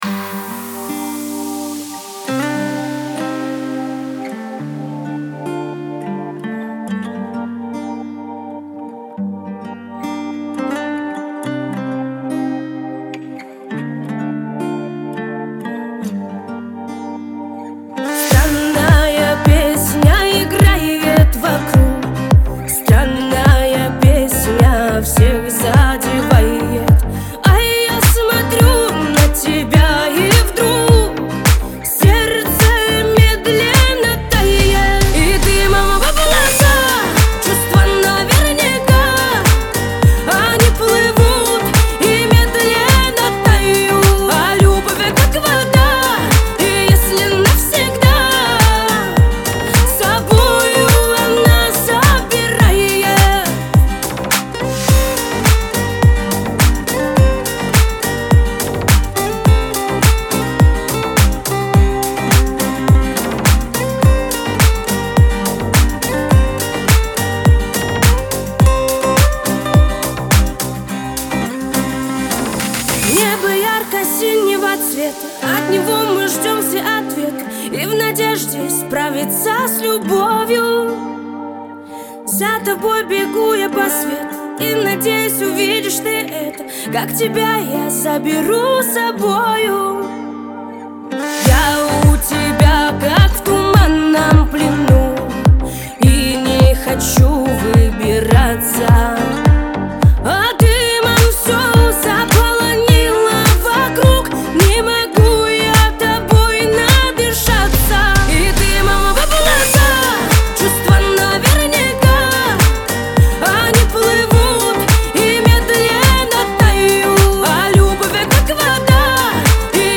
Трек размещён в разделе Русские песни / Рэп и хип-хоп.